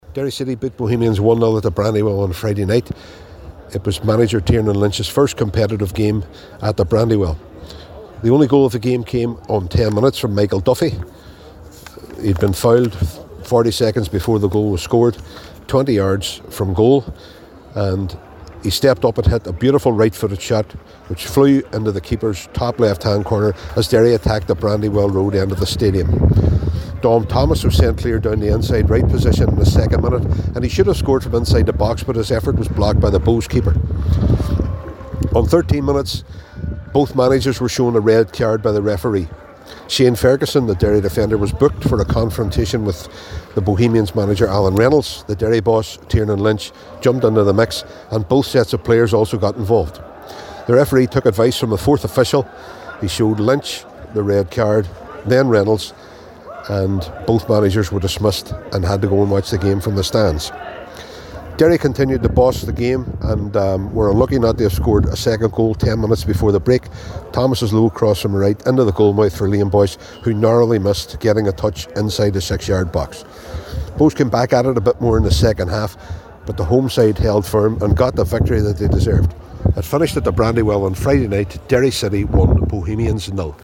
full time report from the Brandywell for Highland Radio Sport…